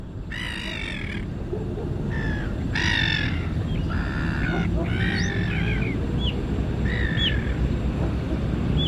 Gaviota Capucho Café (Chroicocephalus maculipennis)
Llamada.
Nombre en inglés: Brown-hooded Gull
Fase de la vida: Adulto
Localización detallada: Laguna San Martin
Condición: Silvestre
Gaviota-capucho-cafe--llamada.mp3